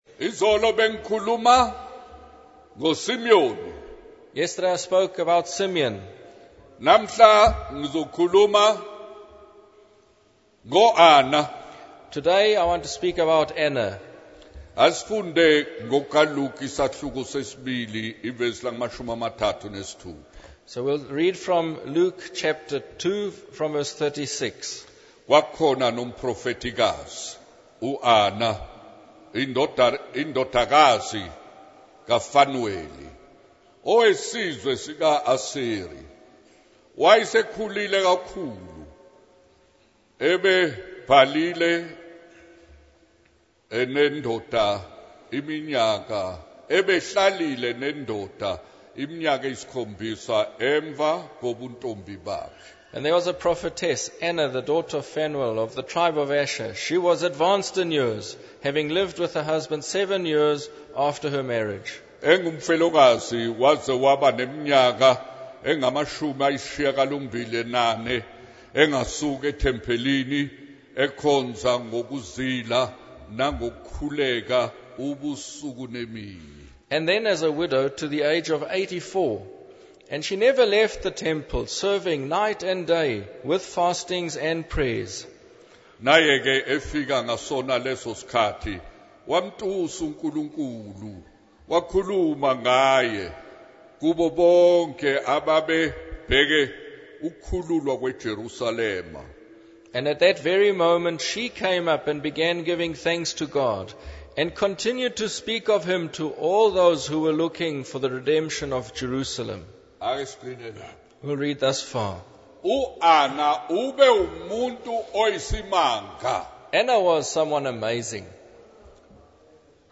In this sermon, the minister tells a story about a woman who confronts a man about breaking his promise to marry her.